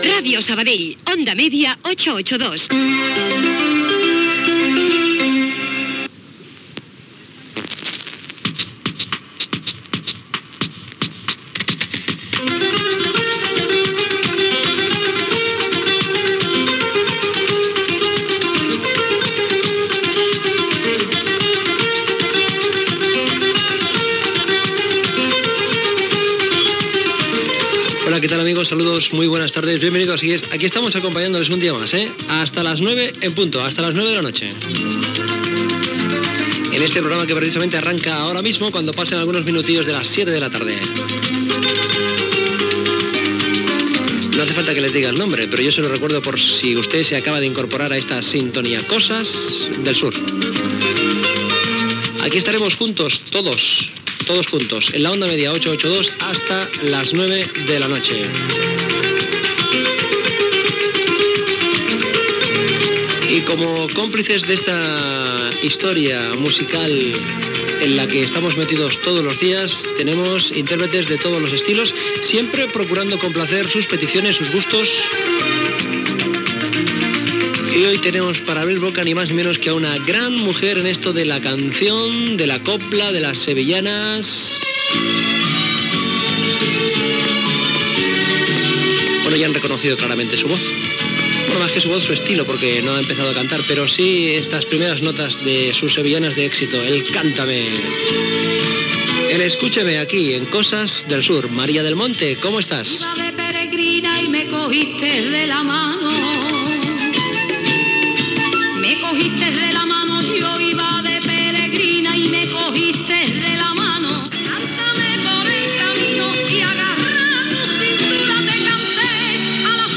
Indicatiu de l'emissora, presentació del programa i de tres temes musicals, indicatiu de l'emissora
Musical